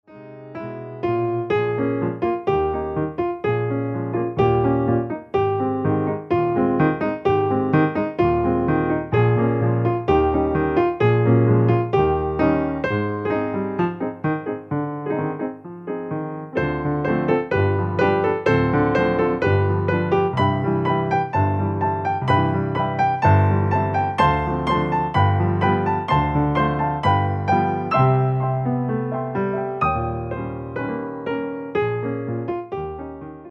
entertainment pianists